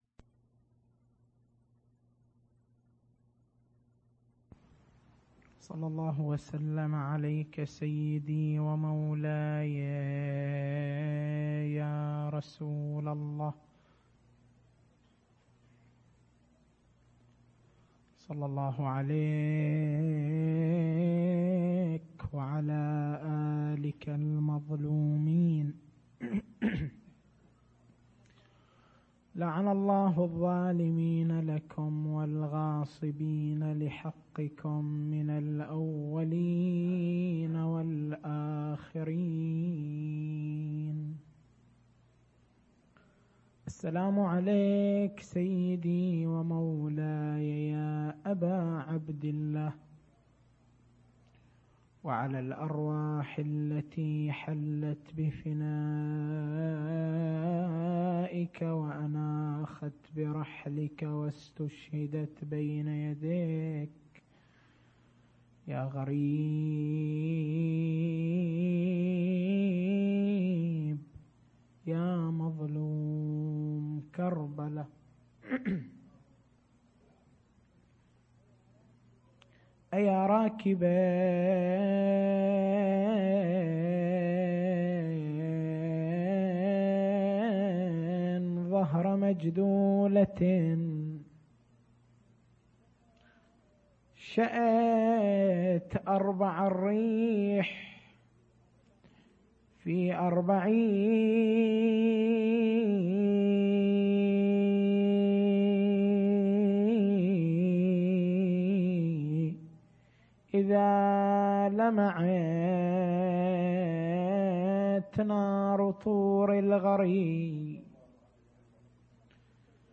تاريخ المحاضرة: 12/09/1419